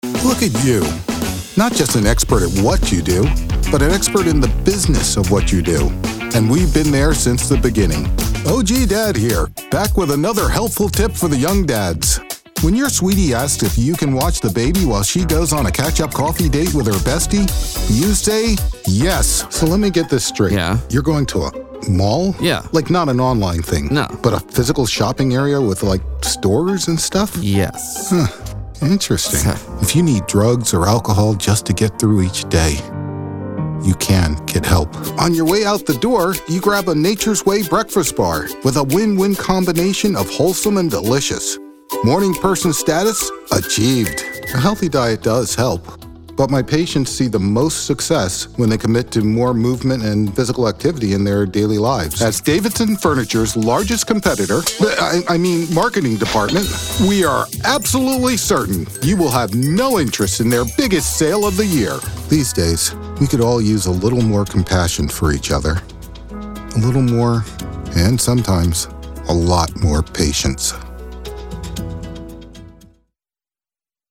My voice is Authoritative, Gritty, Conversational, Commanding, Deep, Trustworthy.
Commercial
Southern accent
Middle Aged